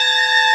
UFO.WAV